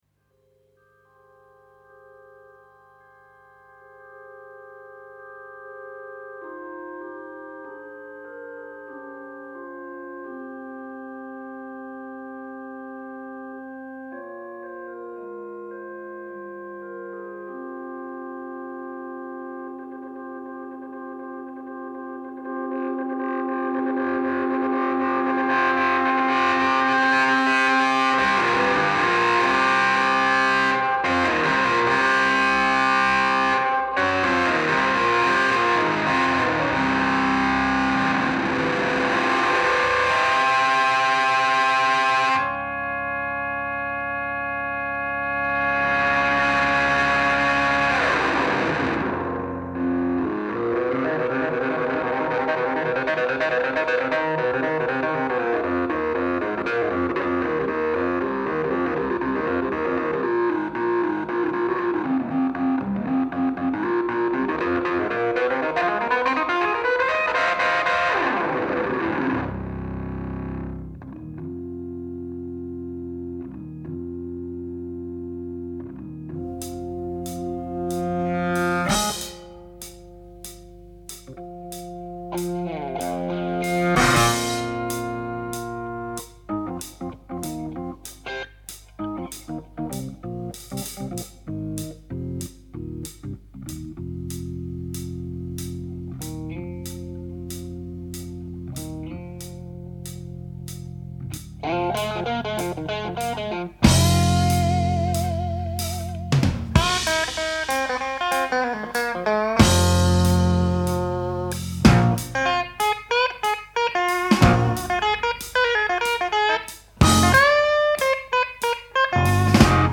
Жанр: Rock, Hard Rock, Heavy Metal